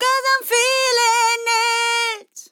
Im feeling it Vocal Sample
Categories: Vocals Tags: DISCO VIBES, dry, english, Feeling, female, Im, it, LYRICS, sample
POLI-LYRICS-Fills-120bpm-Fm-18.wav